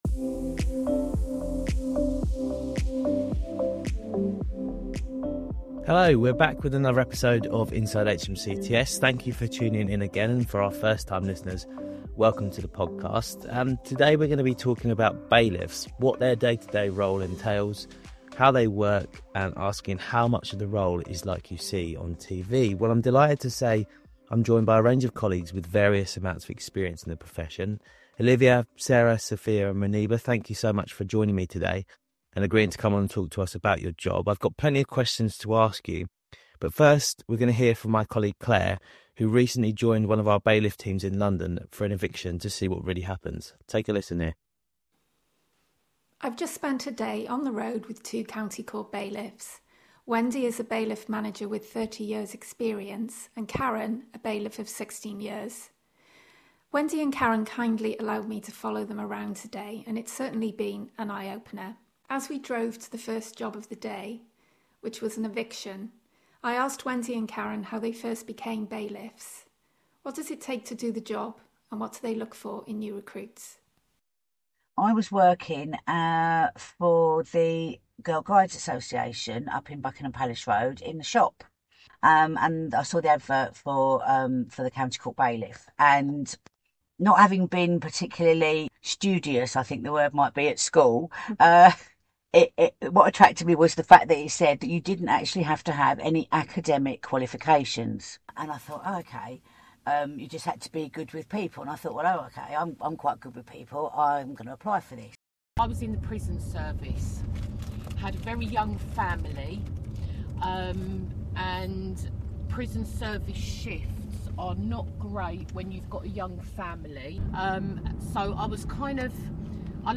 Hear from a range of bailiffs who share their stories and experiences about the profession.